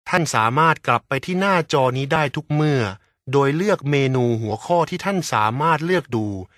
Professioneller thailändischer Sprecher für TV / Rundfunk / Industrie. Professionell voice over artist from Thailand.
Sprechprobe: Werbung (Muttersprache):